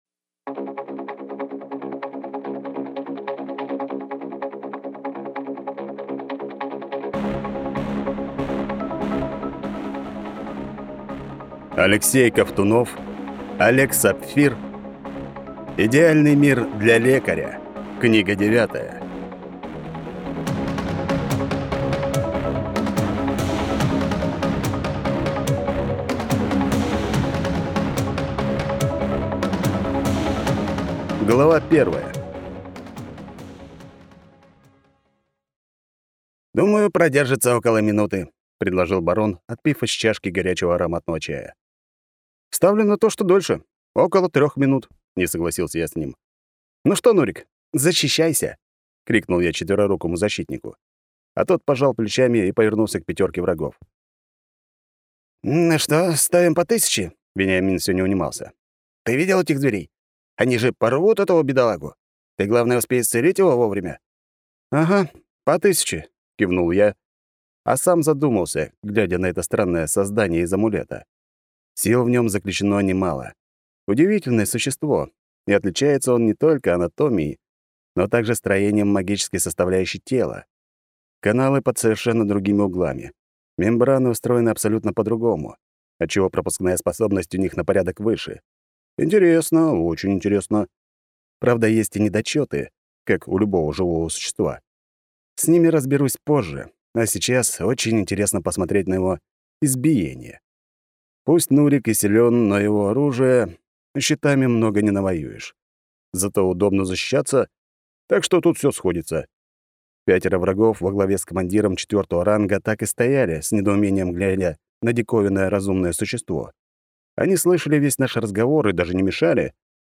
Аудиокнига Идеальный мир для Лекаря 9
Качество озвучивания весьма высокое.